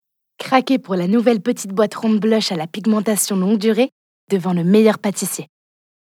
Voix off
Bandes-son